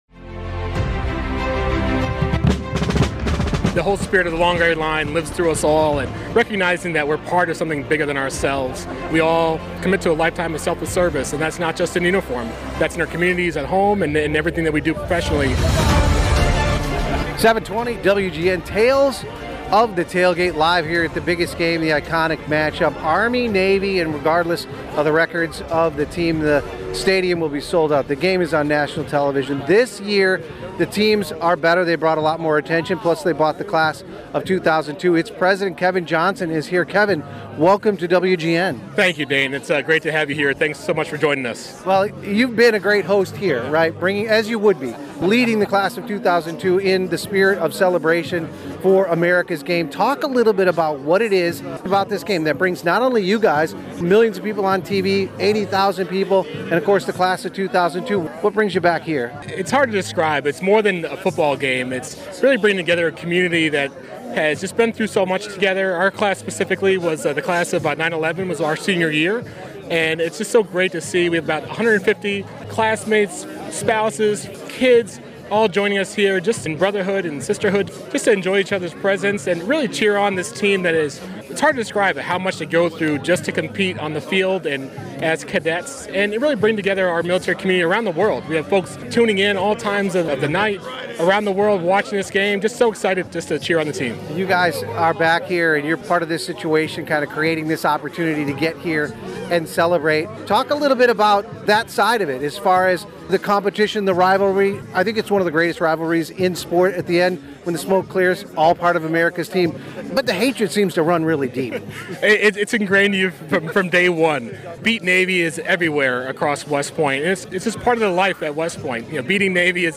live from the tailgate at “America’s Game”